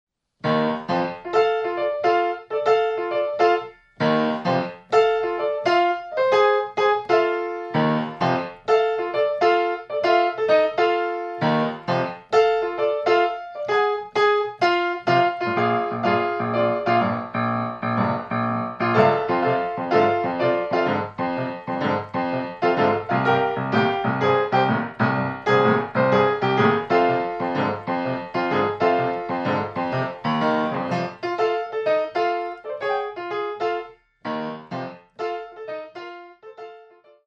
Style: New Orleans Piano